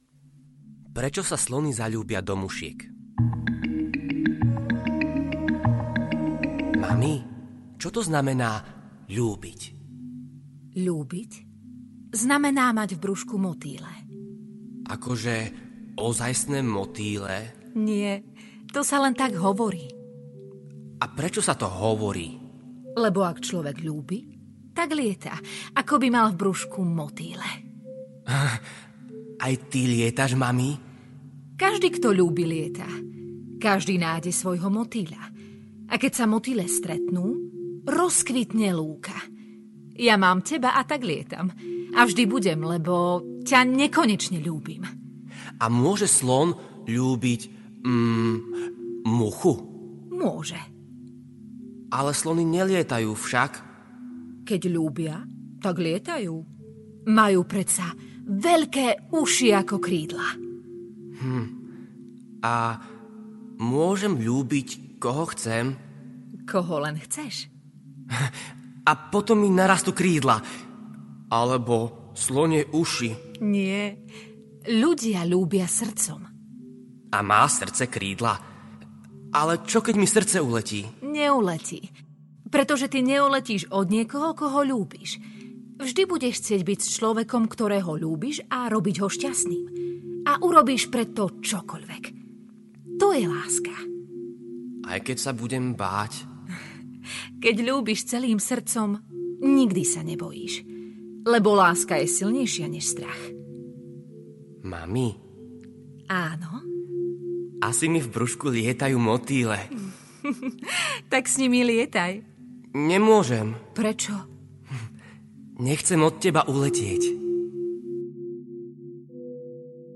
Sú koncipované ako dialóg medzi matkou a dieťaťom ešte v brušku
Ukázka z knihy